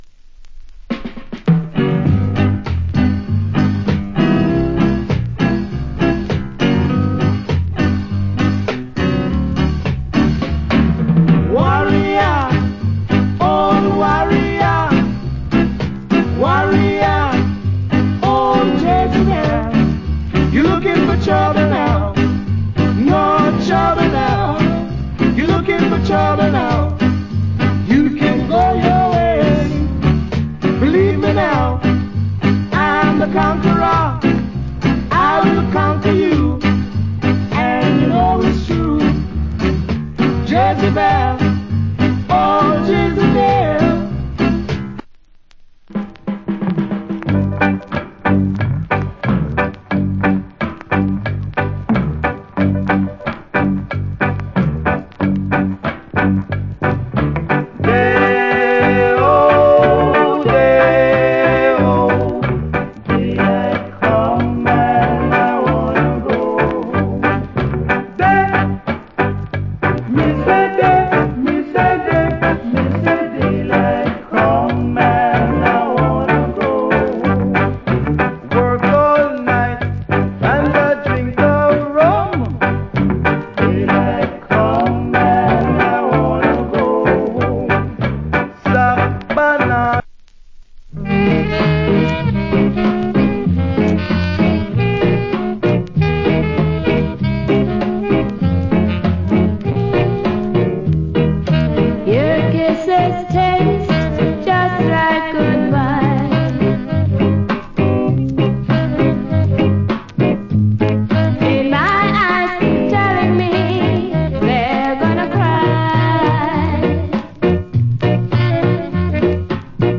Great Rock Steady.